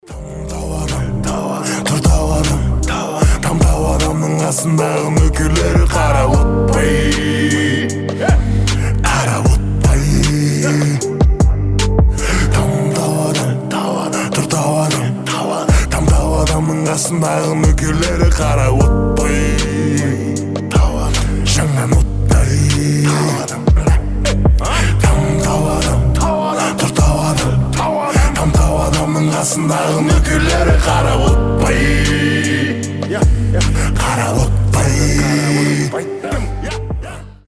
пацанские
качающие